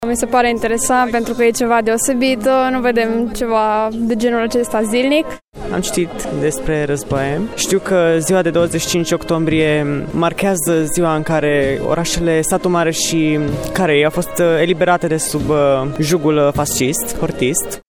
Un ceremonial militar și religios a avut loc la Cimitirul Șprenghi, unde, după alocuțiunile oficiale, au fost depuse coroane de flori.
Primarul municipiului Brașov, George Scripcaru.